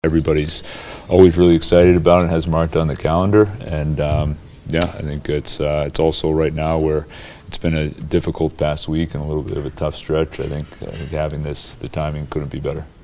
Coach Dan Muse says the Dads Trip comes at a good time.
nws0580-dan-muse-dads-trip.mp3